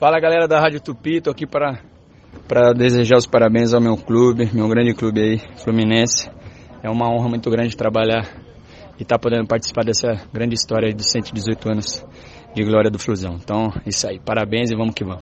O meia Nenê um dos principais jogadores do elenco tricolor também falou na Super Rádio Tupi sobre o aniversário do tricolor: